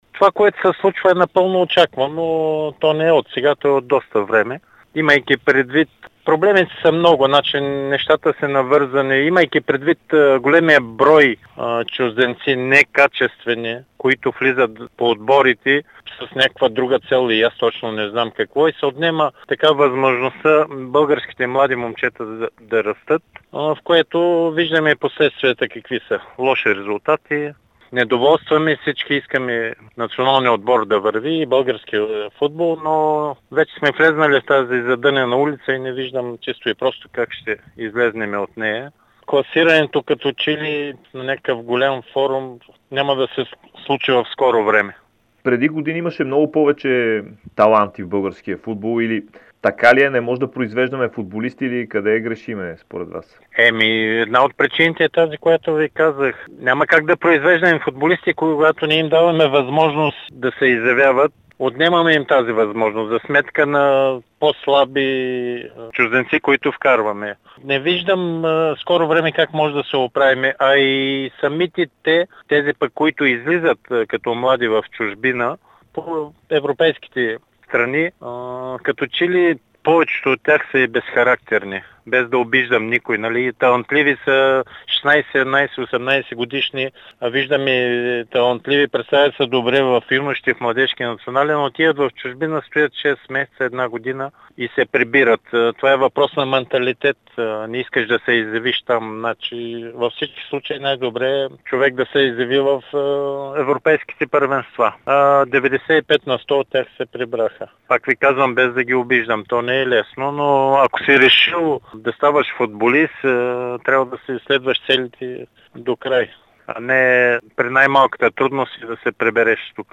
Легендата на Славия и българския футбол Андрей Желязков даде ексклузивно интервю пред Дарик радио и dsport, в което коментира ситуацията в националния отбор.